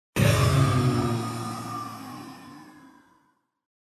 Звуки ифрита
На этой странице собраны звуки Ифрита — одного из самых опасных существ Нижнего мира в Minecraft. Здесь вы можете скачать или слушать онлайн его угрожающее рычание, звуки атак, возгорания и другие эффекты.